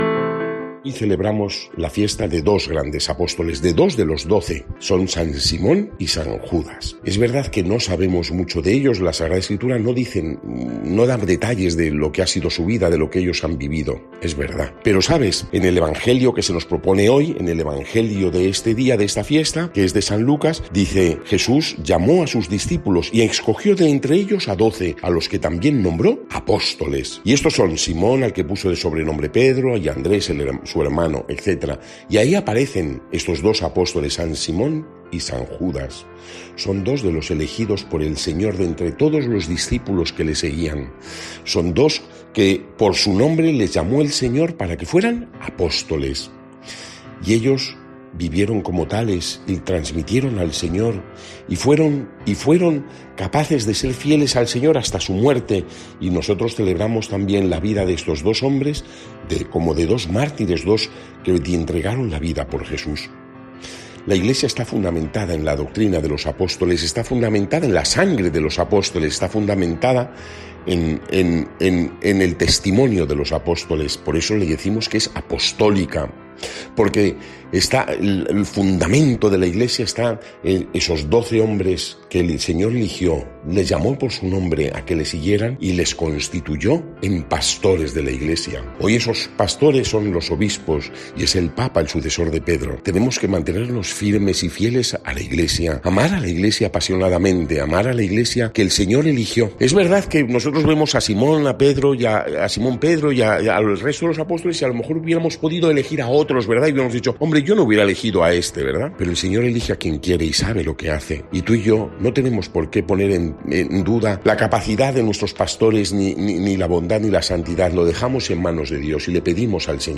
Lectura del santo evangelio según san Lucas 6, 12-19